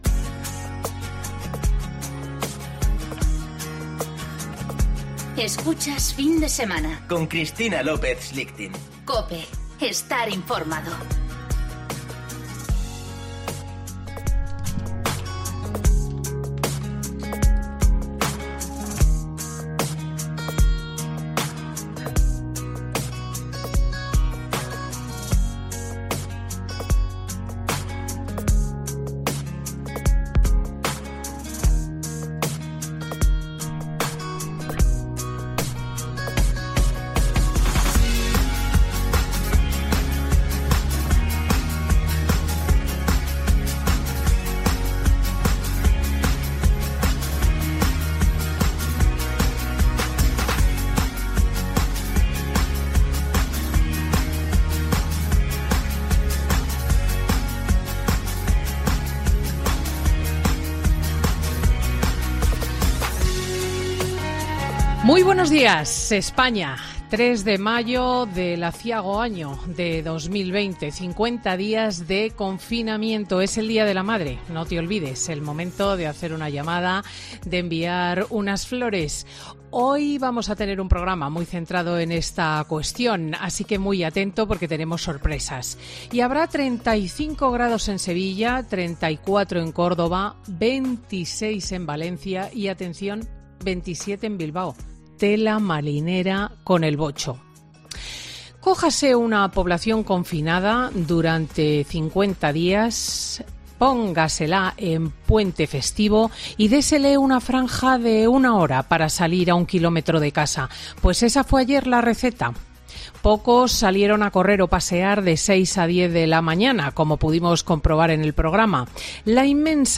Monólogo de Cristina López Schlichting
La presentadora de 'Fin de Semana' relata como vivió el primer día de paseos del confinamiento en España este sábado